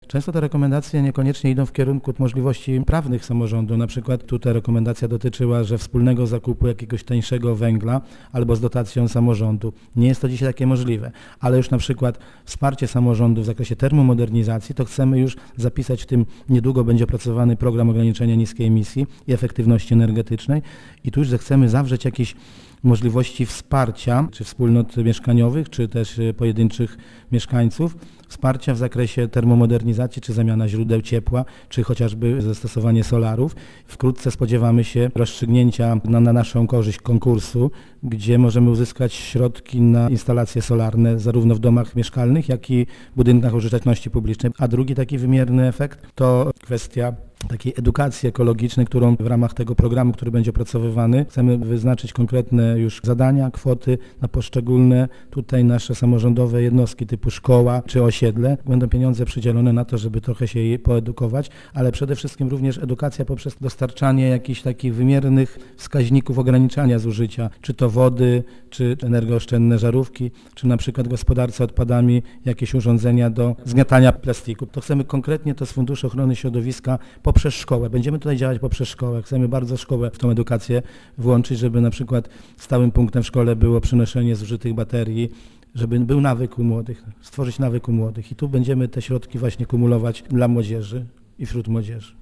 Efekty ubiegłorocznych narad już są, choć trzeba przy tym pamiętać, że samorząd nie może zrealizować wszystkich rekomendacji mieszkańców - podkreśla burmistrz Rejowca Fabrycznego Stanisław Bodys: